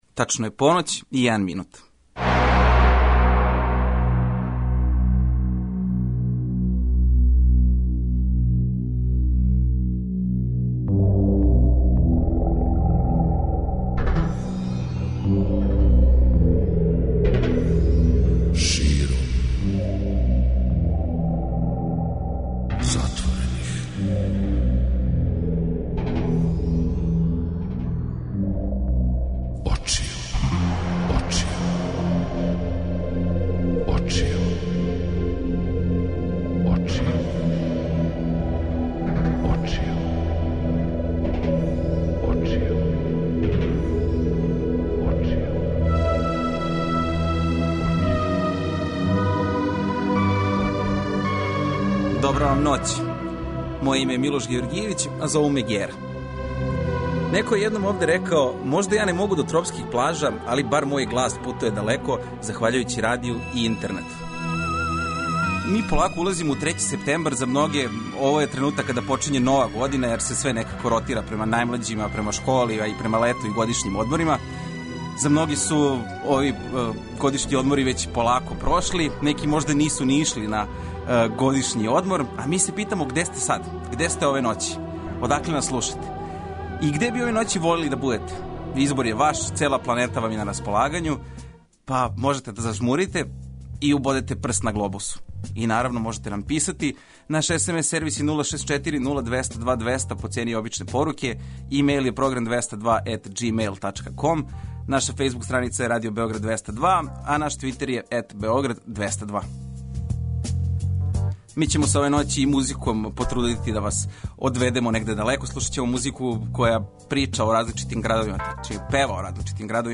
Највећим хитовима поп и рок музике улазимо у први викенд септембра!
Ове ноћи ћаскаћемо на најразличитије теме из живота и слушаћемо највеће хитове домаће и светске музичке продукције. Поделите са нама где сте ноћас и шта радите, да ли сте у проводу или ноћ проводите уз вашу омиљену радио станицу.